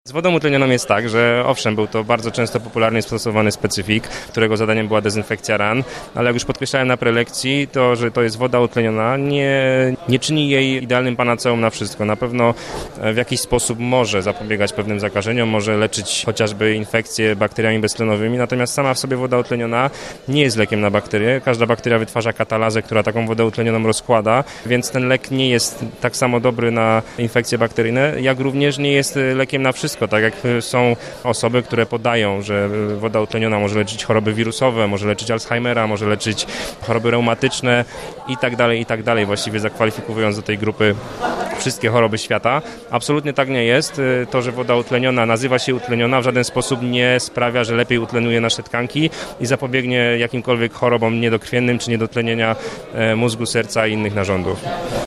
prelekcja2